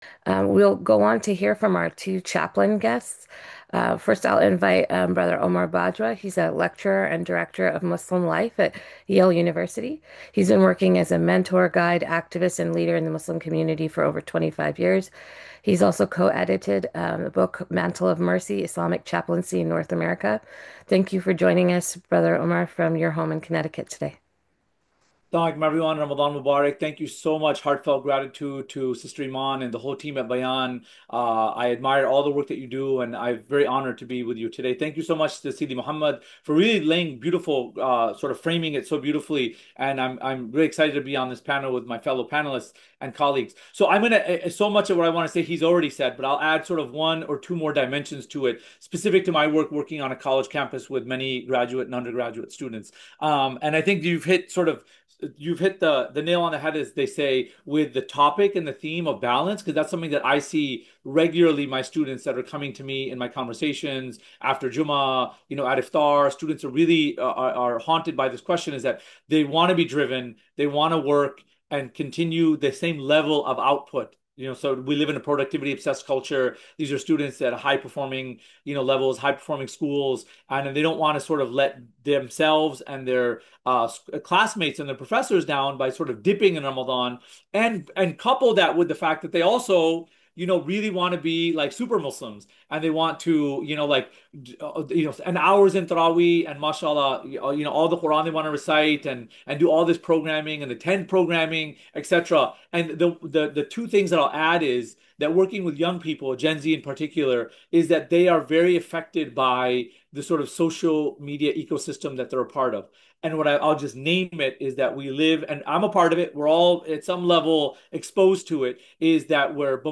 This is a curated audio library of short reflections from Bayan’s scholars, teachers, and students.
Each recording begins with a simple declaration: “This is a Voice of Bayan.”